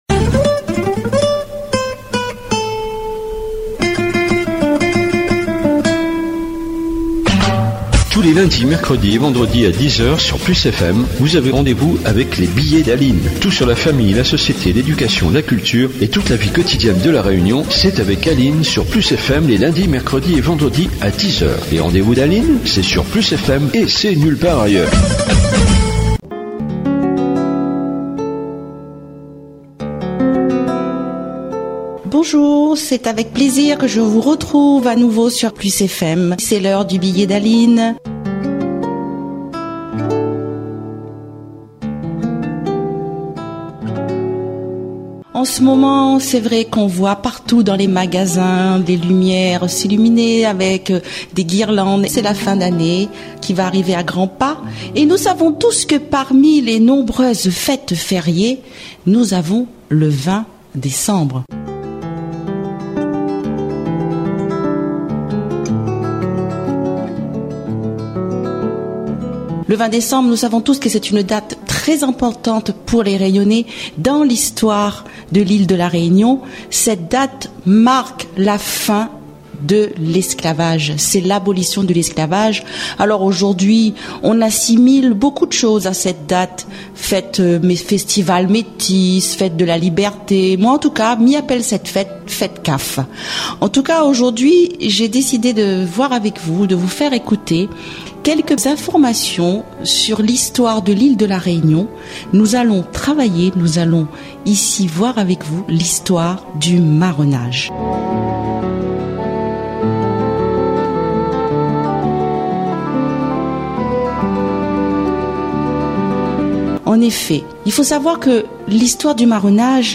L'enregistrement de l'émission de radio